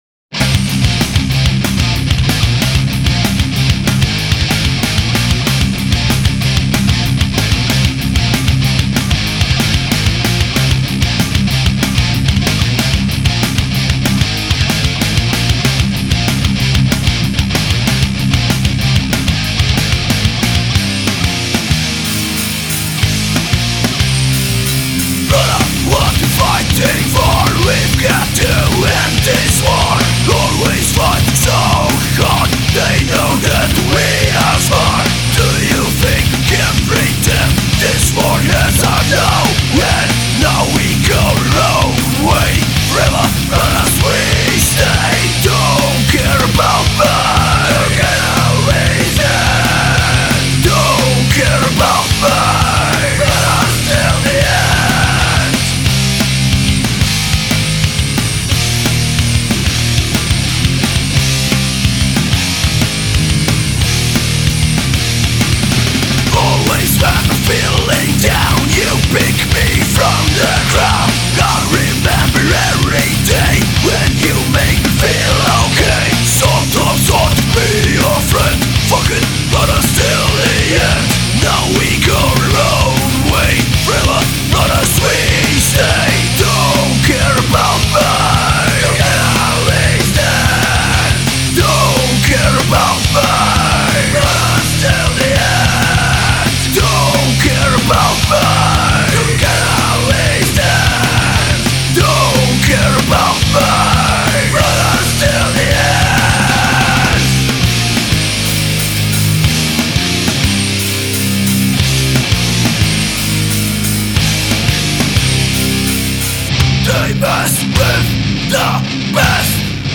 Vocals
Guitar
Bassguitar
Drums